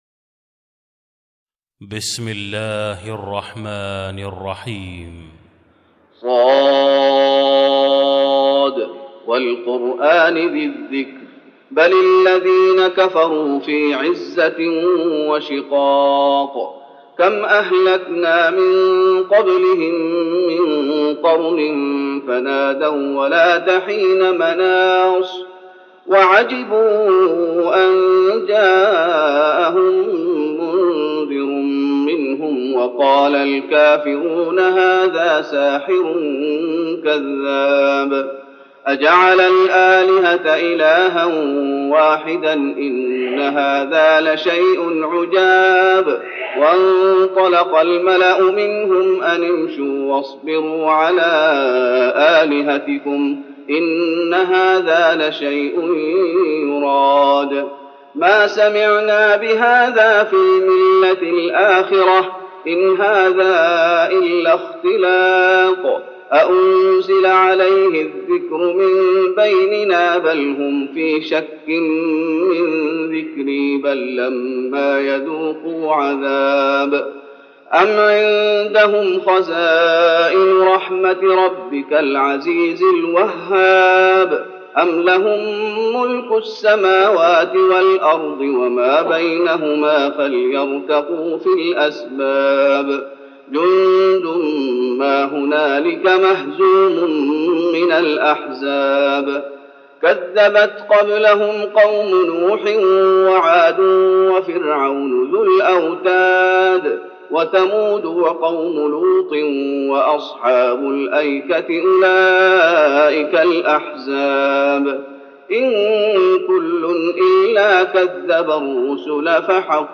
تراويح رمضان 1413هـ من سورة ص Taraweeh Ramadan 1413H from Surah Saad > تراويح الشيخ محمد أيوب بالنبوي 1413 🕌 > التراويح - تلاوات الحرمين